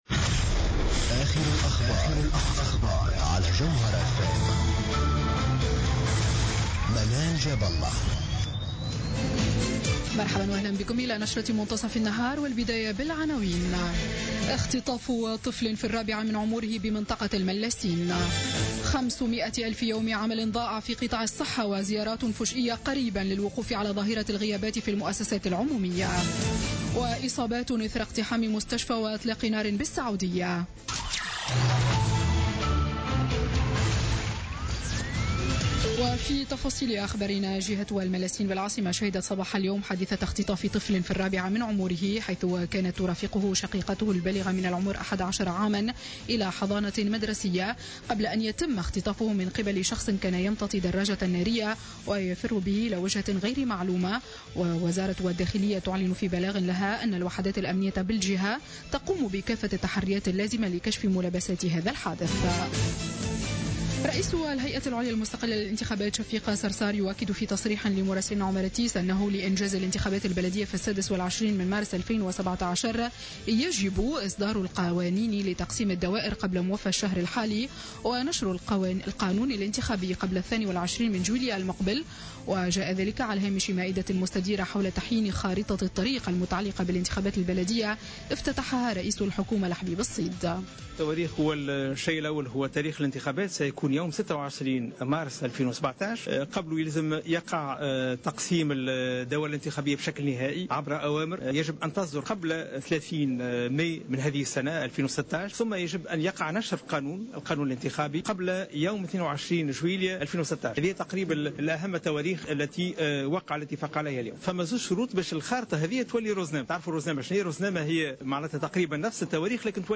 نشرة أخبار منتصف النهار ليوم الثلاثاء 17 ماي 2016